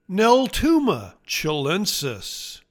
Pronounciation:
Nel-TOO-ma chi-LEN-sis